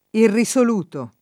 irriSol2to], var. peraltro non com. nel sign. quasi antiq. di «insoluto, non risolto» (detto di questione), e rara nel sign. usuale di «dubbioso, incerto, insicuro» (detto di persona) — variazioni simili nei der. -lutezza, -luzione